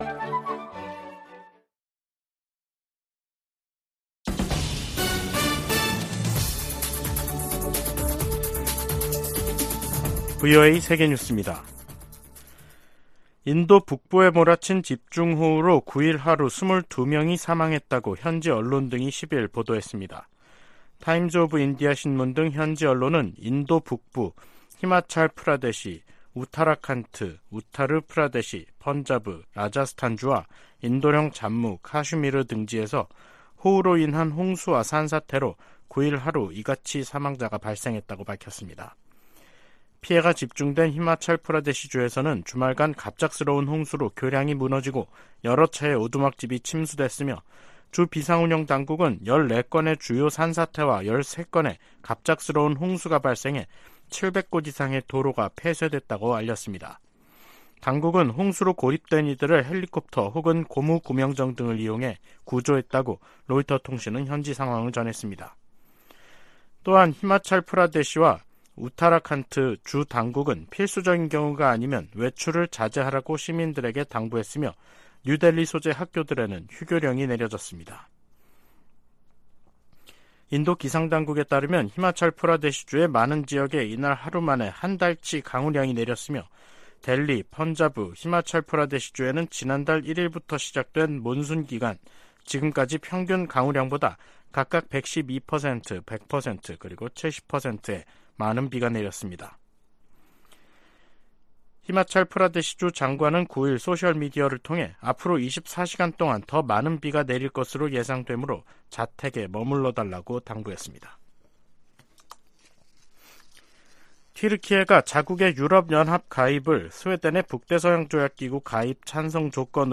VOA 한국어 간판 뉴스 프로그램 '뉴스 투데이', 2023년 7월 10일 3부 방송입니다. 미국과 한국이 오는 18일 서울에서 핵협의그룹 출범회의를 엽니다. 백악관은 미한 핵협의그룹(NCG) 회의의 중요성을 강조하며 고위급 인사가 참여할 것이라고 밝혔습니다. 북한 당국이 일본 후쿠시마 오염수 방출 계획을 비난하는데 대해 미국 전문가들은 북한의 핵시설 오염수 관리가 더 큰 문제라고 지적했습니다.